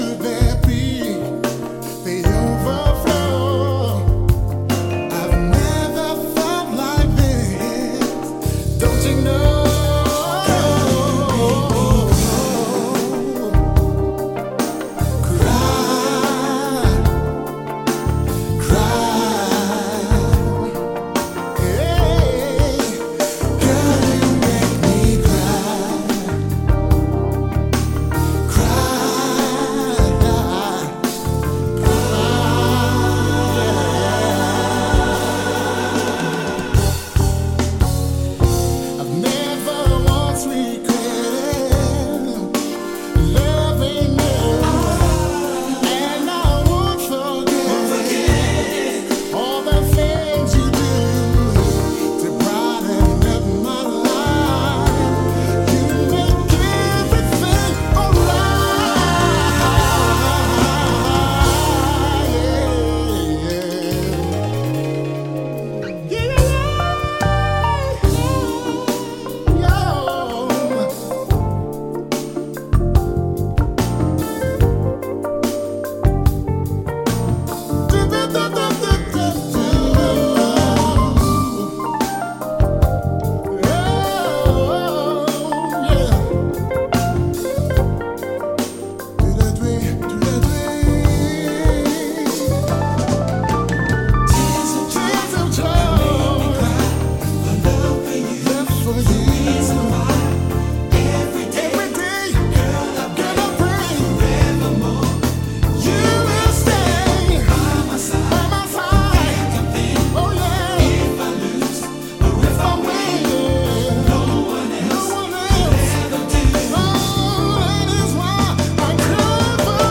Urban Contemporary ~ Neo-Soul Featured Edition!